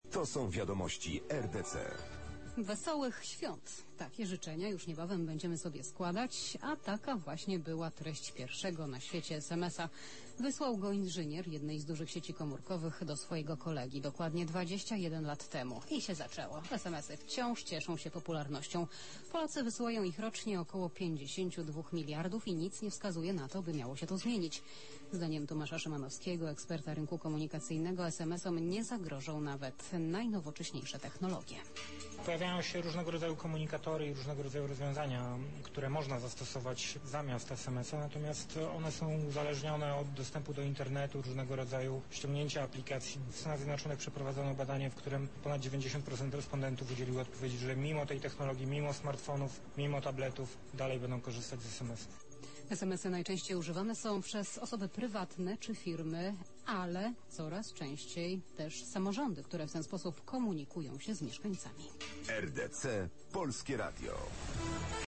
opowiadał o tym narzędziu na antenie Polskiego Radia Dla Ciebie.